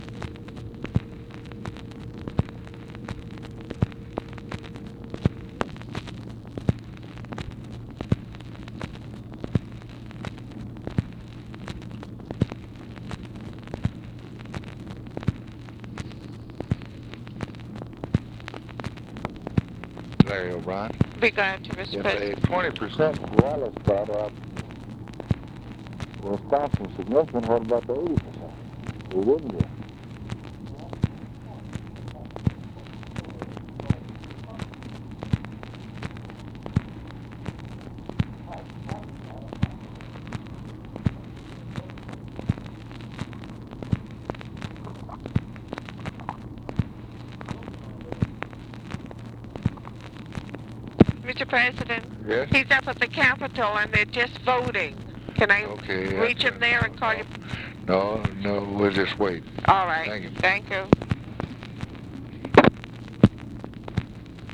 Conversation with TELEPHONE OPERATOR and OFFICE CONVERSATION
Secret White House Tapes | Lyndon B. Johnson Presidency